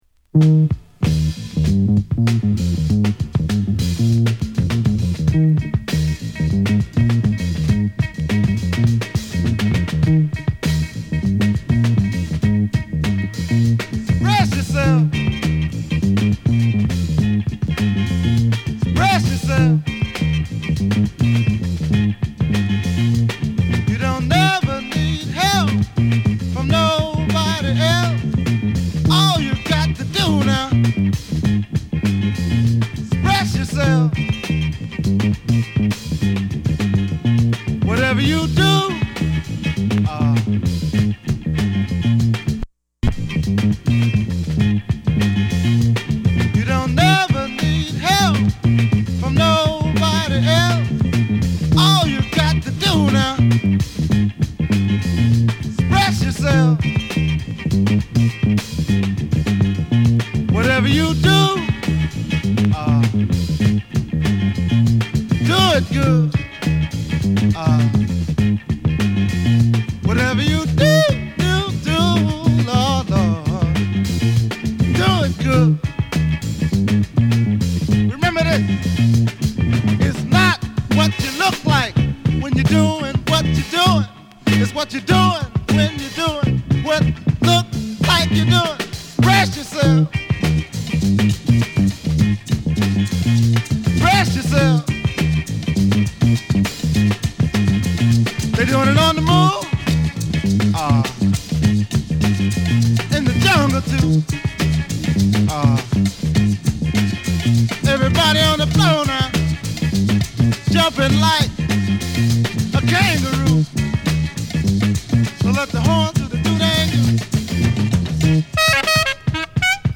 レアグルーヴクラシックの7インチリイシュー